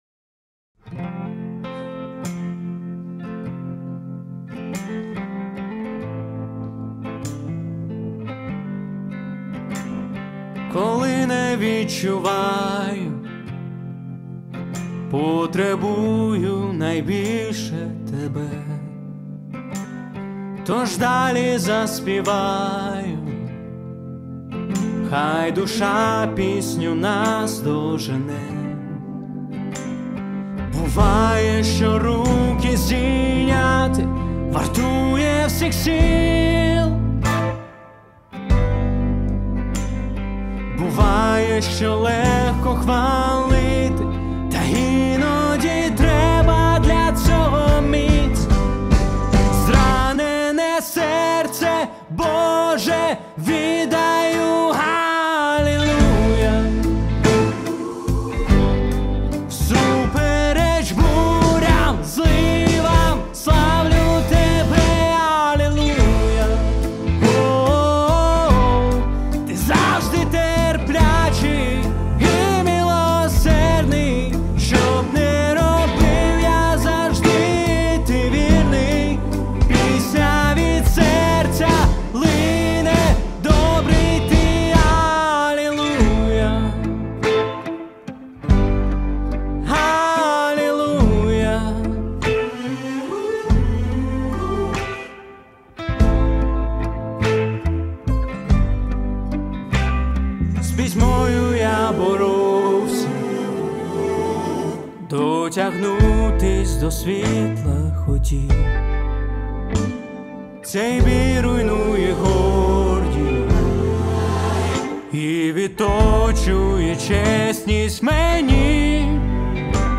834 просмотра 287 прослушиваний 19 скачиваний BPM: 72